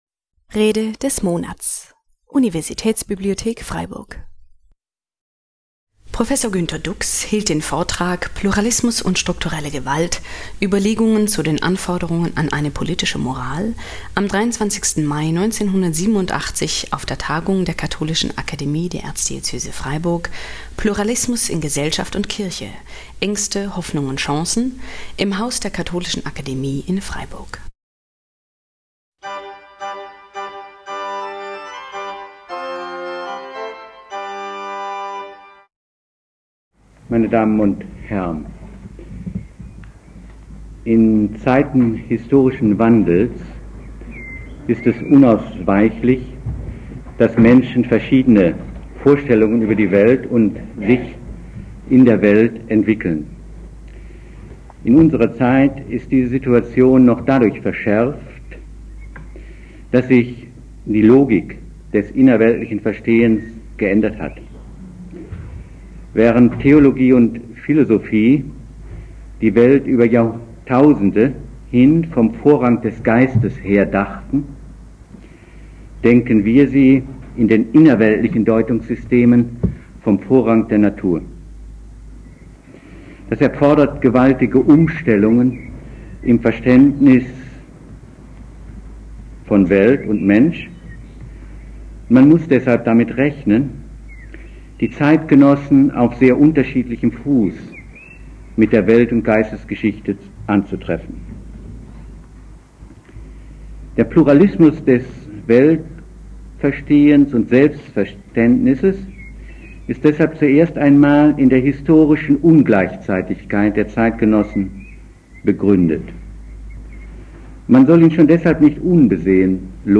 Pluralismus und strukturelle Gewalt : Überlegungen zu den Anforderungen an eine politische Moral (1987) - Rede des Monats - Religion und Theologie - Religion und Theologie - Kategorien - Videoportal Universität Freiburg